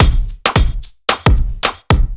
OTG_Kit 4_HeavySwing_110-D.wav